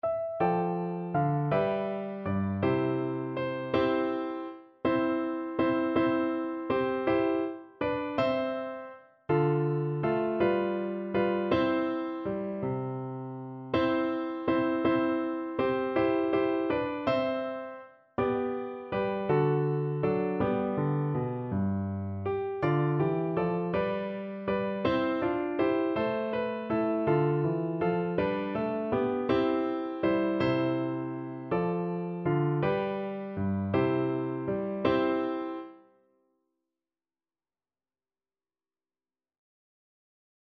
Traditional Trad. Er is een jarig, hoera, hoera Flute version
3/4 (View more 3/4 Music)
G5-G6
C major (Sounding Pitch) (View more C major Music for Flute )
One in a bar .=c.54
Traditional (View more Traditional Flute Music)